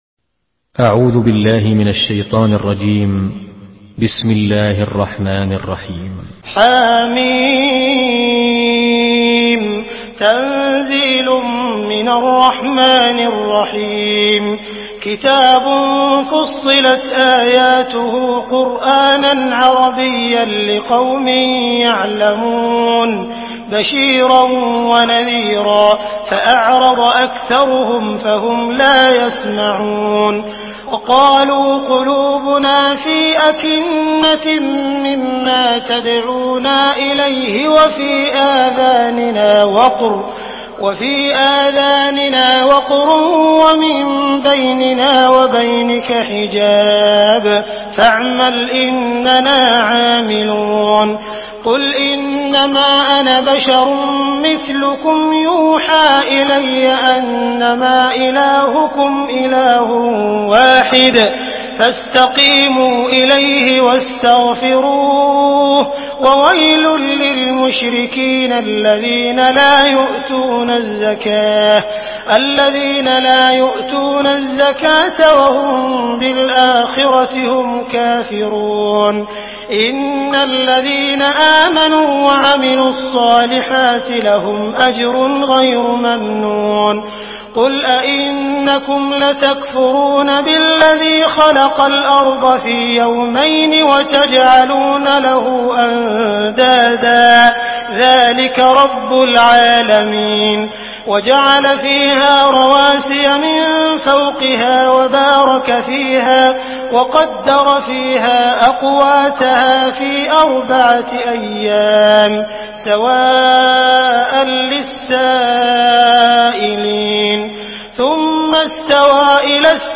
Sourate Fussilat MP3 à la voix de Abdul Rahman Al Sudais par la narration Hafs
Une récitation touchante et belle des versets coraniques par la narration Hafs An Asim.
Murattal Hafs An Asim